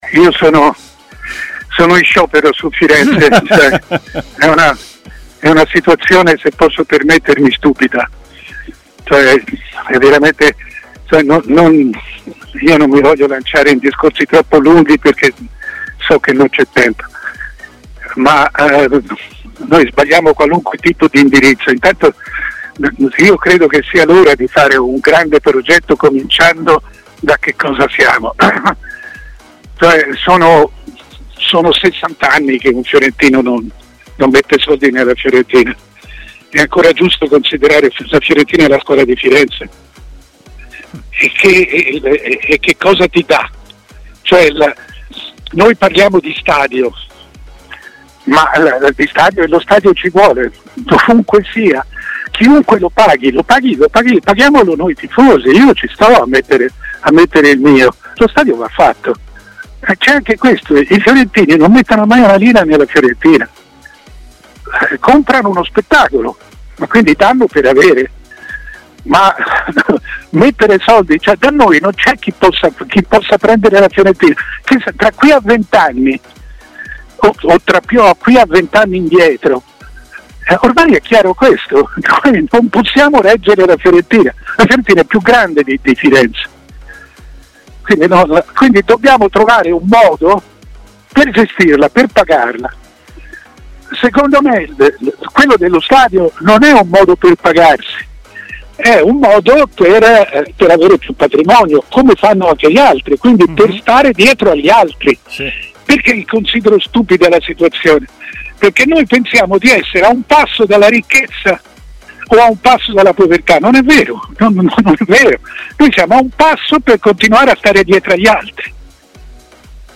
Il direttore Mario Sconcerti è intervenuto in diretta ai microfoni di Tmw Radio.